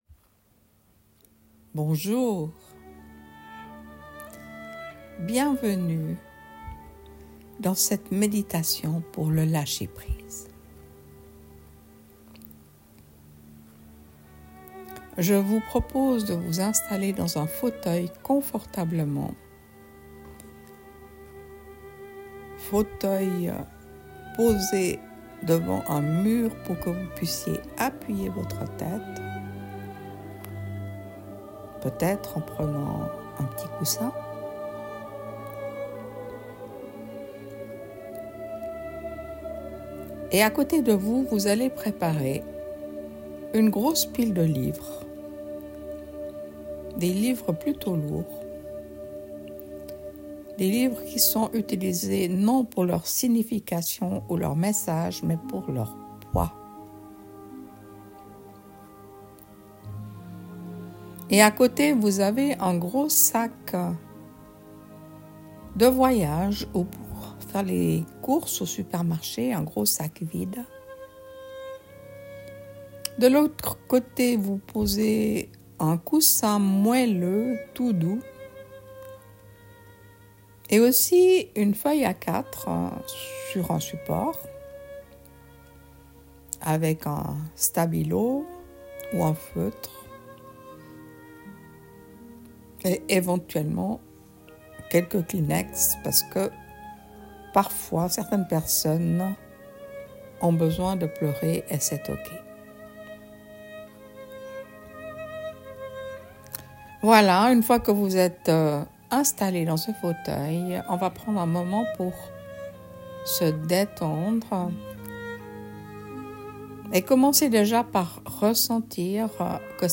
Meditation pour lâcher prise
Meditation_lacher-prise.mp3